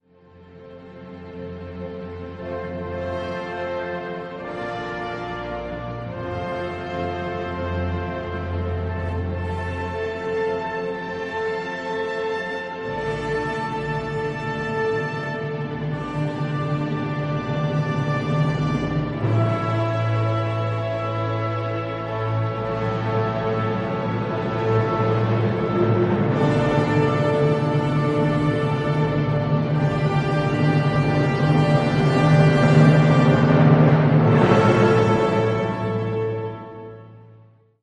Symphony No. 4 in D minor Op. 120